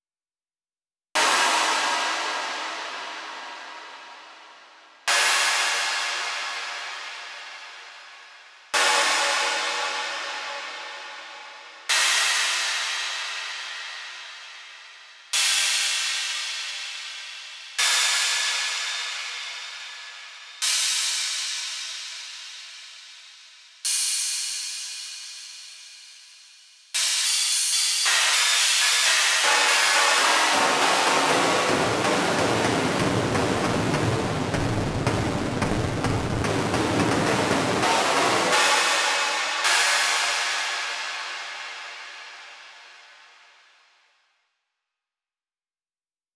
Cymbals and metallic percussions synthesis lab